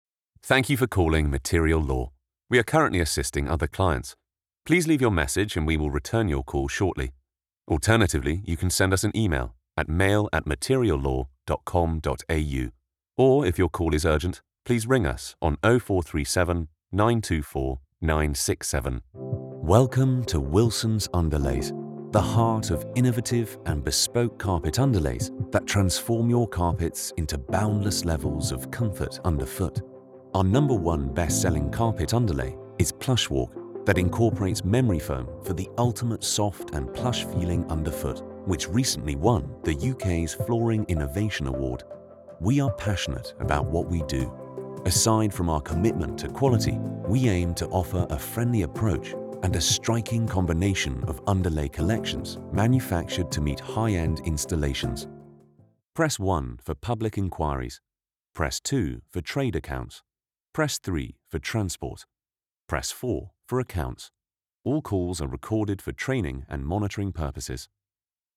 Englisch (Britisch)
Kommerziell, Tief, Unverwechselbar, Warm
Telefonie